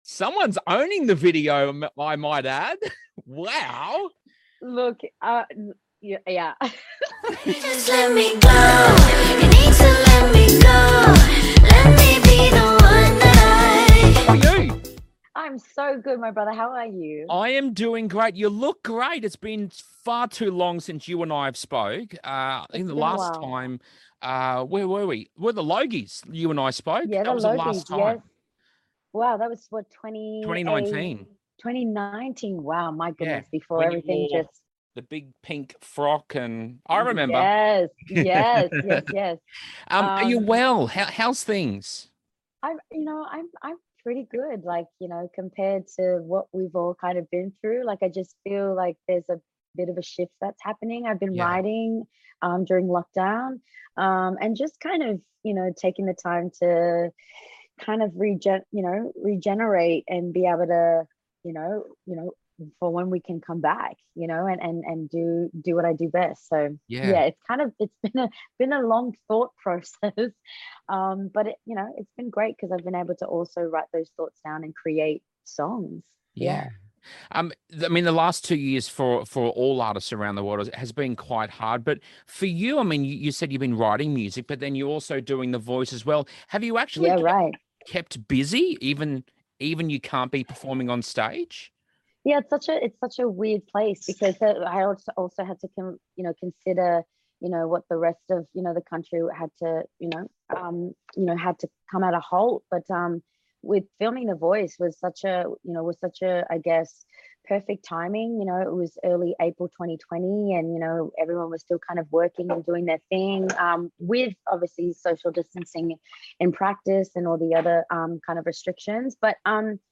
Jessica Mauboy Interview